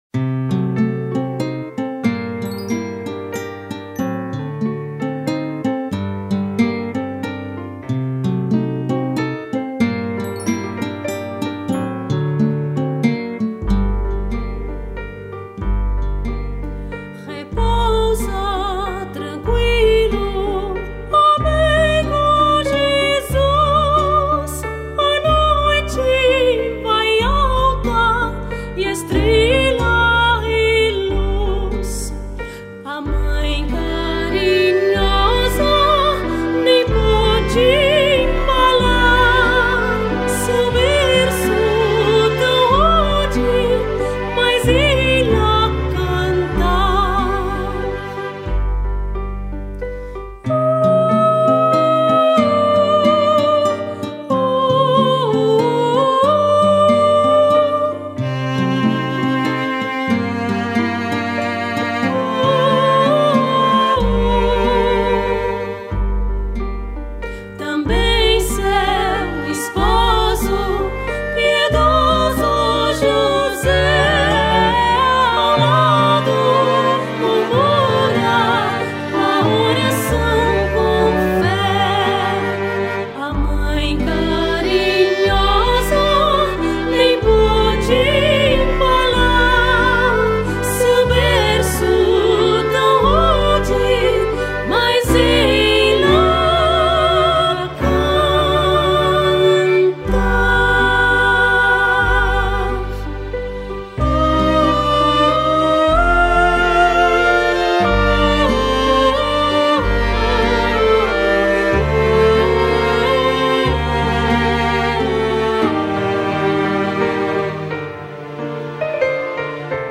1047   05:30:00   Faixa:     Canção Religiosa